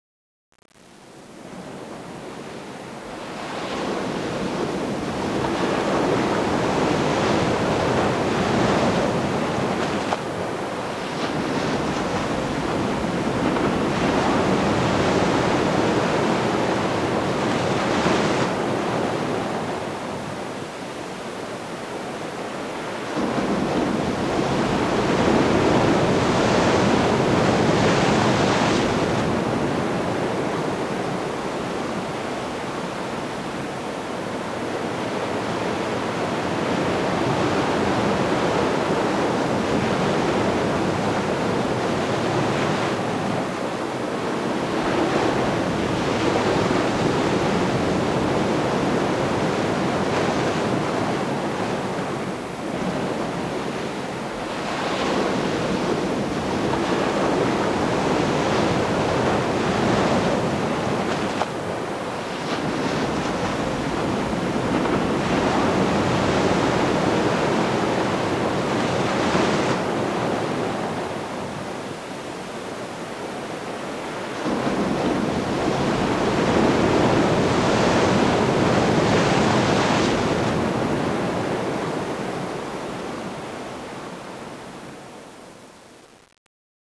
waves2.wav